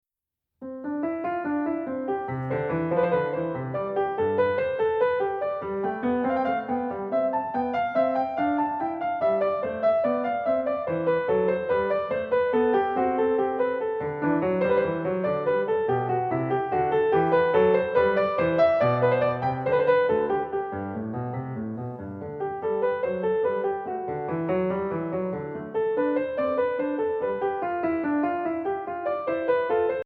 Voicing: Piano with Audio Access